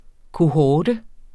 kohorte substantiv, fælleskøn Bøjning -n, -r, -rne Udtale [ koˈhɒːdə ] Oprindelse fra latin cohors (genitiv -tis) 'indhegnet plads; skare, afdeling soldater' Betydninger 1.